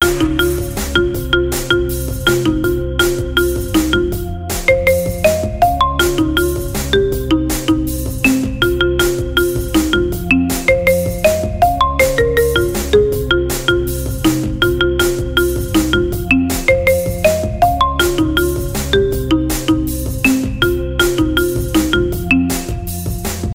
happy_marimba_uncut.wav